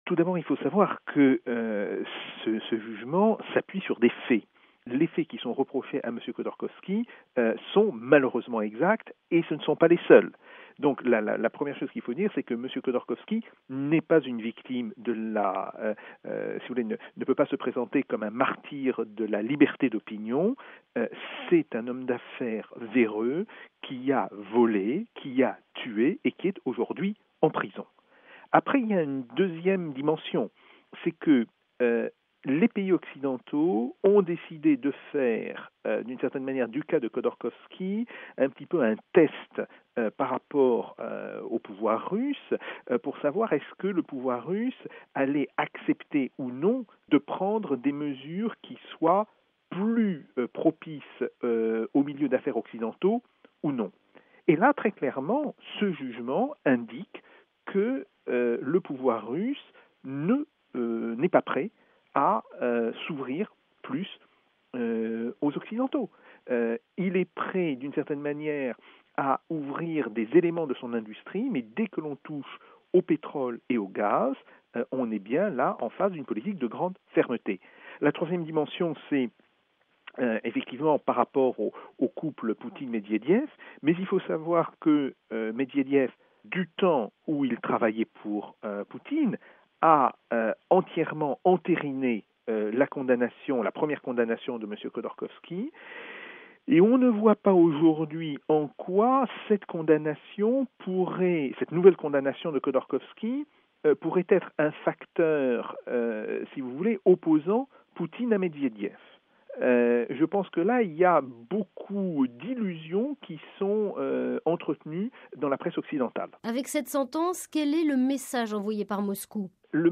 L’analyse de Jacques Sapir, Directeur d’études à l'École des Hautes Études en Sciences Sociales et spécialiste de la Russie RealAudio